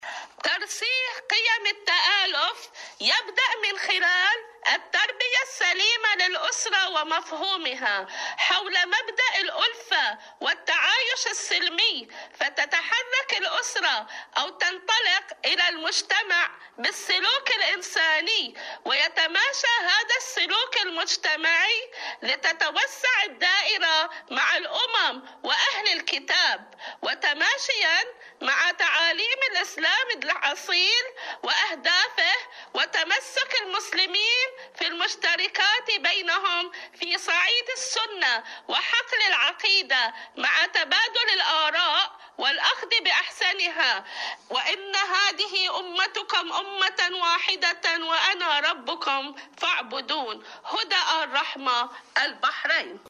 معكم على الهواء /مشاركة هاتفية